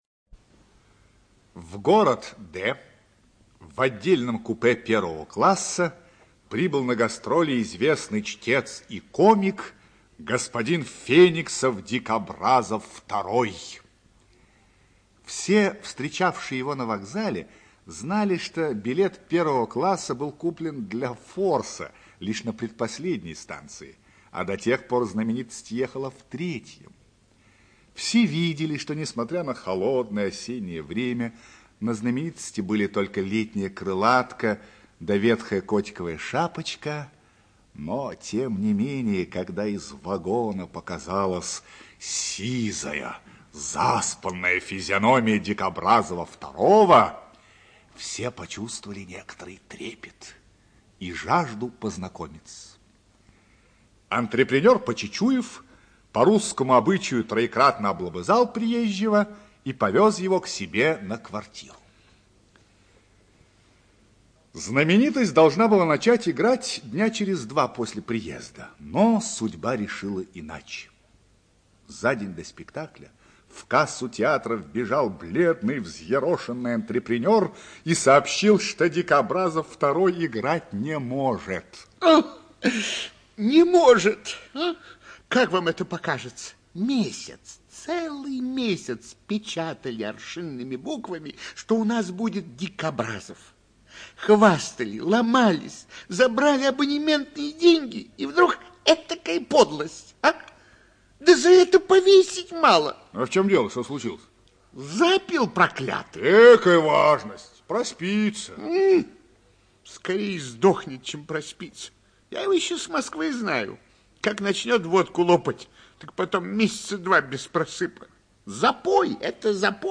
ЧитаютПлятт Р., Грибов А., Попов В., Абдулов А.
ЖанрРадиоспектакли